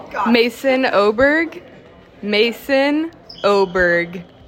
Name Pronunciation: